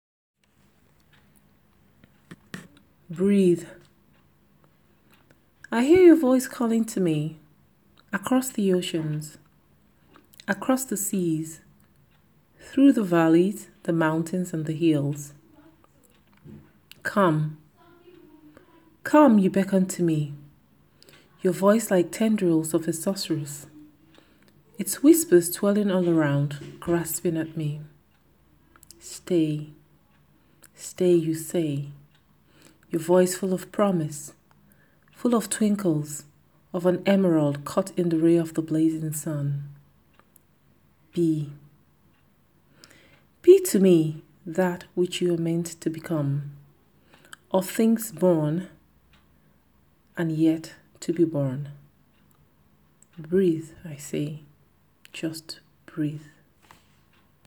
I have no particular reason to cringe at my own voice, other than the fact that I think my voice warbles instead of coming out smoother when I sing, but hey, I gotta love what I have.
So I have to love it’s dusky tone and squeeze the best out of my juke box.